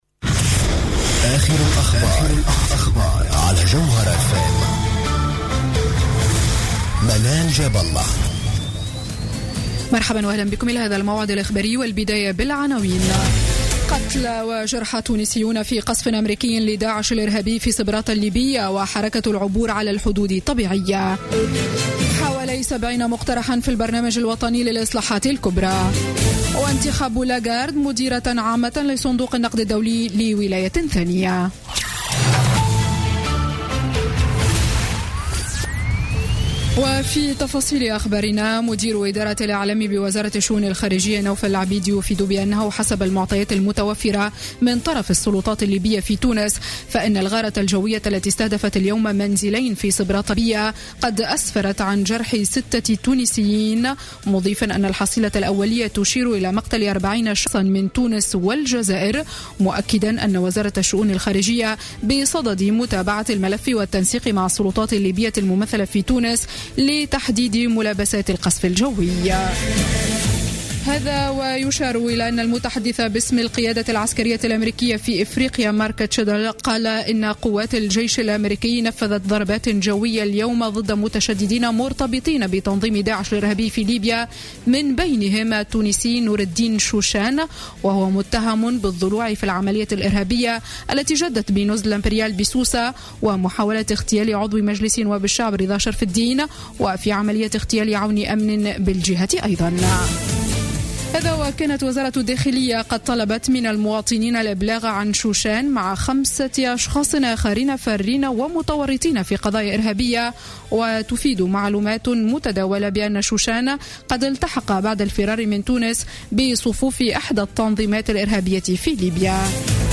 نشرة أخبار السابعة مساء ليوم الجمعة 19 فيفري 2016